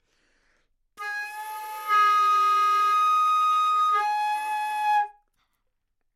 长笛单音（吹得不好） " 长笛A4音准不好
描述：在巴塞罗那Universitat Pompeu Fabra音乐技术集团的goodsounds.org项目的背景下录制。
Tag: 好声音 单注 多样本 A4 纽曼-U87 长笛